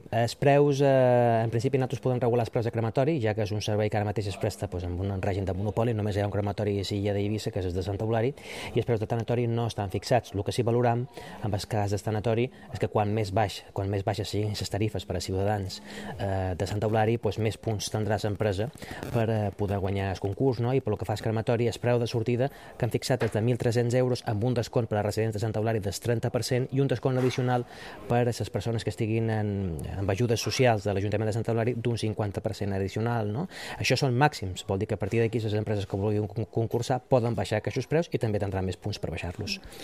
Concejal de Urbanismo Mariano Juan- precios de los servicios Crematorio/Tanatorio